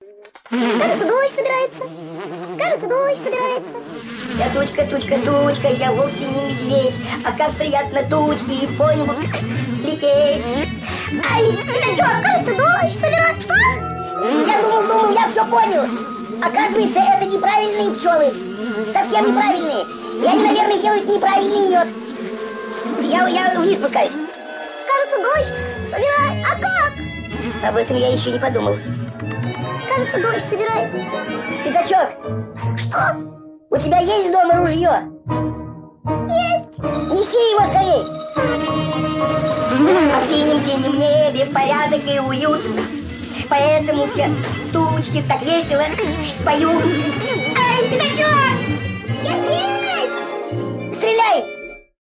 Это милая песенка для малышей.